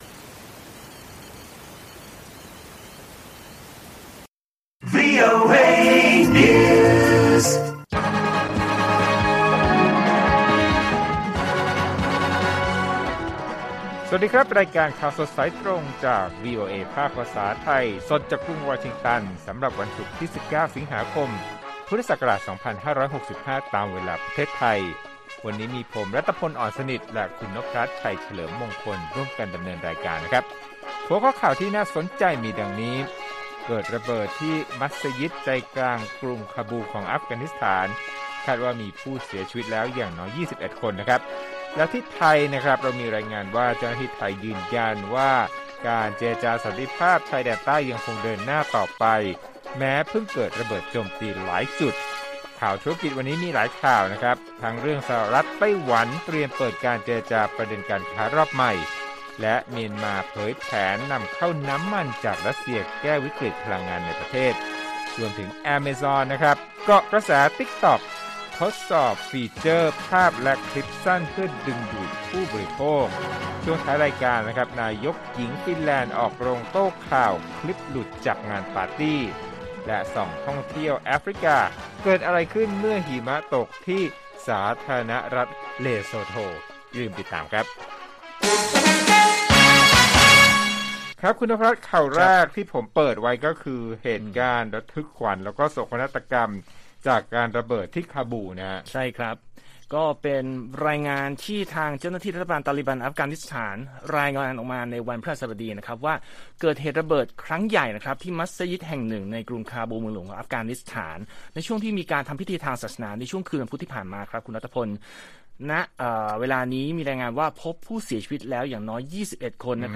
ข่าวสดสายตรงจากวีโอเอไทย 6:30 – 7:00 น. วันที่ 19 ส.ค. 65